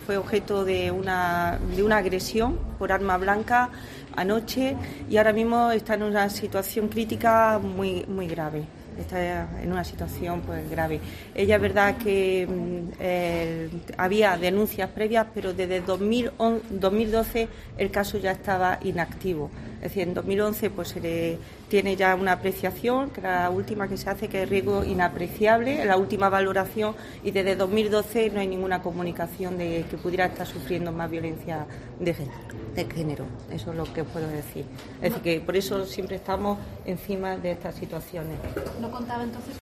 Inmaculada López Calahorro, subdelegada del gobierno